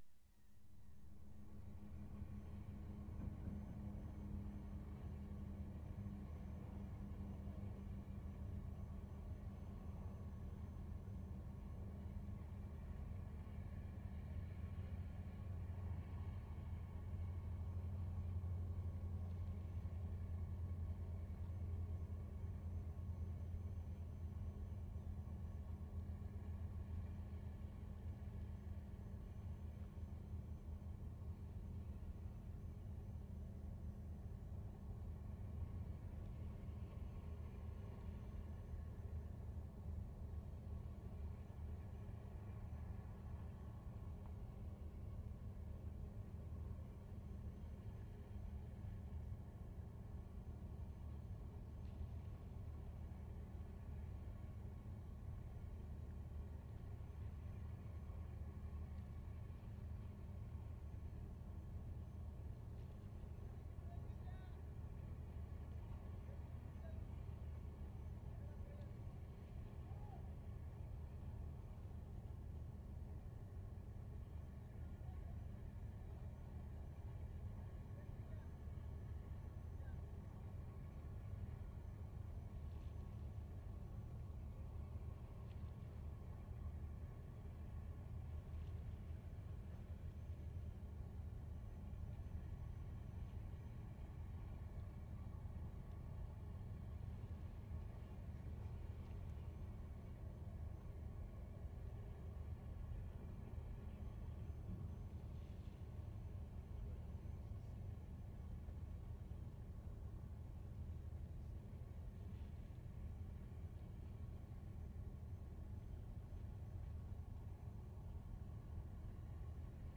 WATERFRONT / CP WHARF Sept. 13, 1972
3. This was recorded from a different position, no water sounds, just distant city throb and hum.
1'10" distant voices.